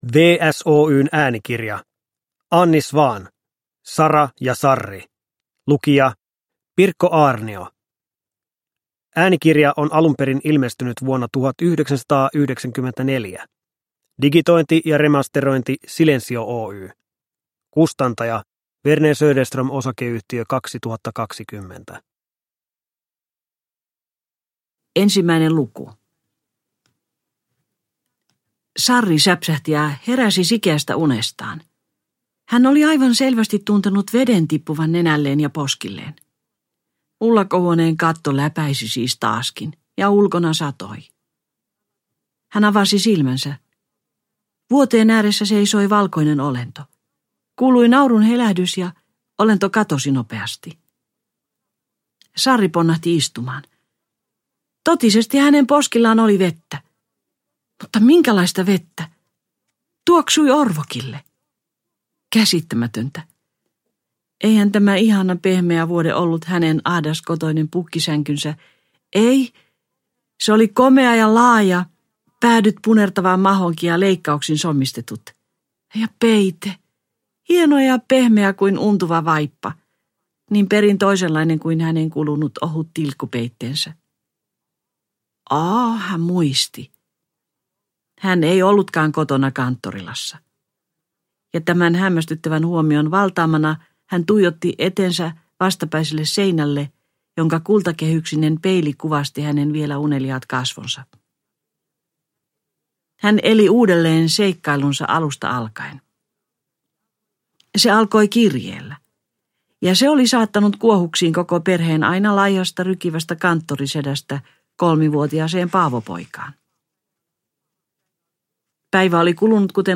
Sara ja Sarri – Ljudbok – Laddas ner
Sara ja Sarri on ilmestynyt alun perin vuonna 1927, äänikirja on äänitetty vuonna 1994, digitoitu 2020.